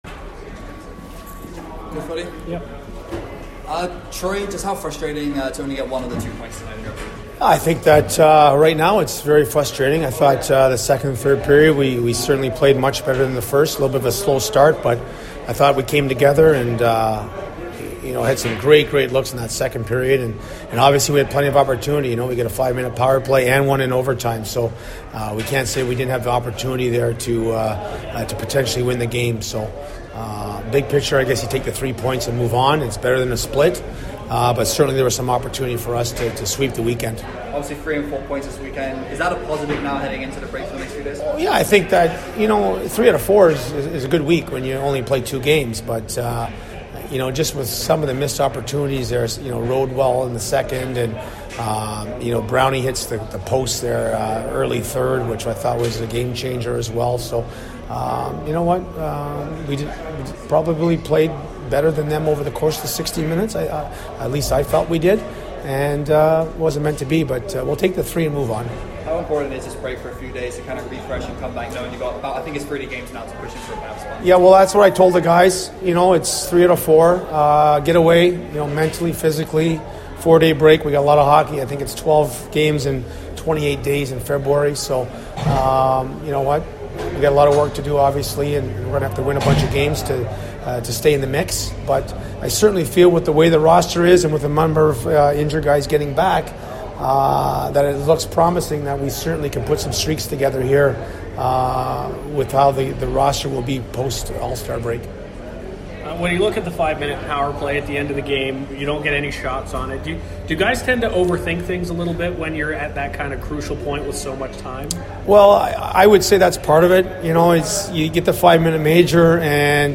full post game interview